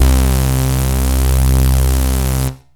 SYNTH BASS-1 0018.wav